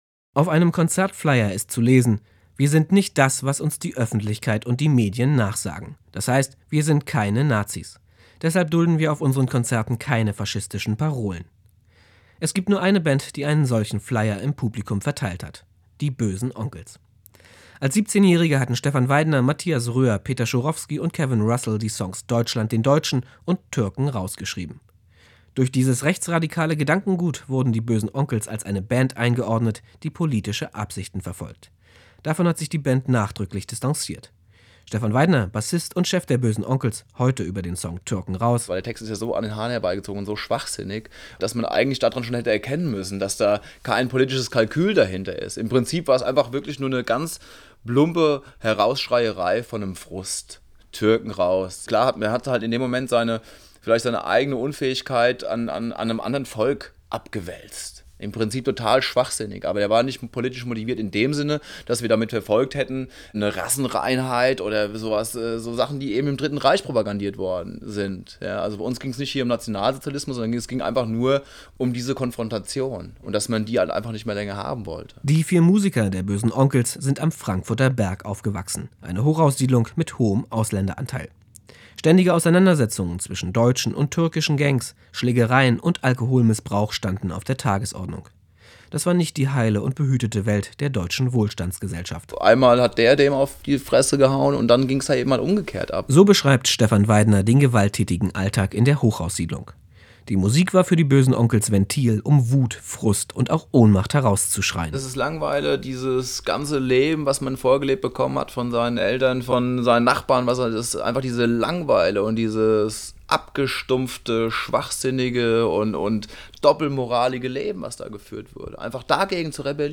Anbei hört hier ein paar Worte der Band zum Release des Albums, direkt aus dem Presse-Kit zur Veröffentlichung.